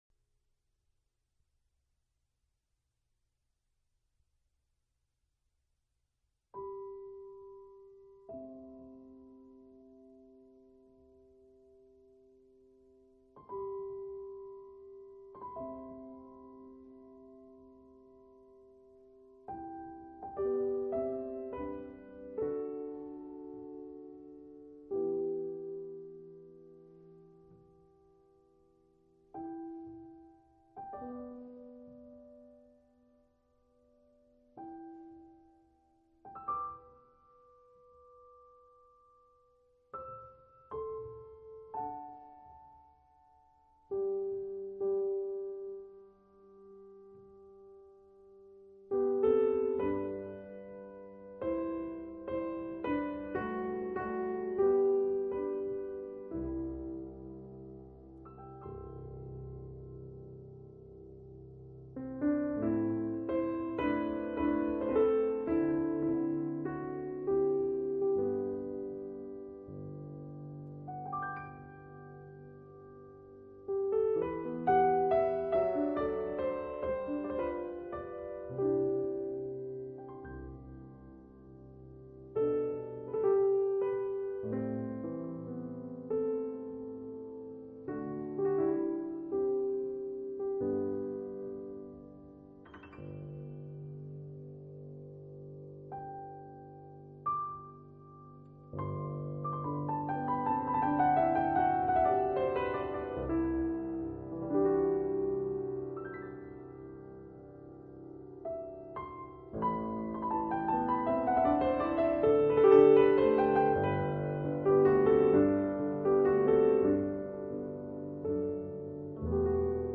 被美 国唱片评鉴评为：美丽的演奏，丰富的琴音让听者如沐春风。